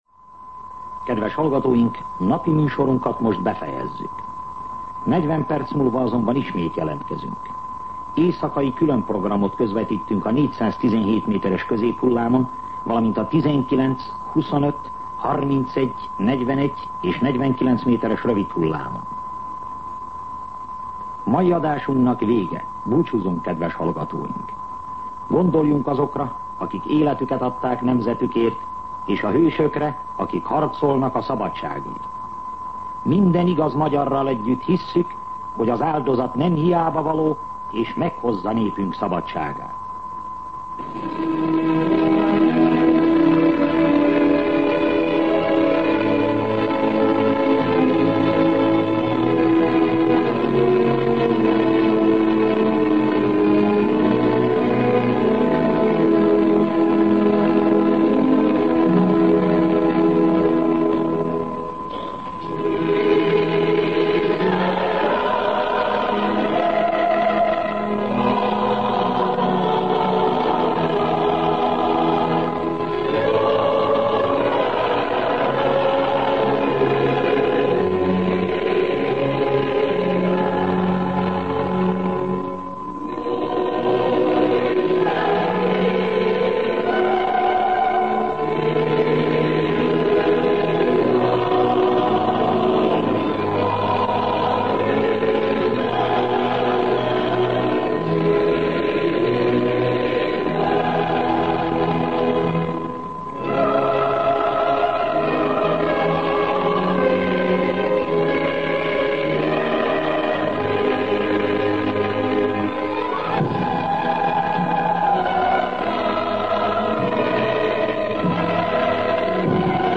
Himnusz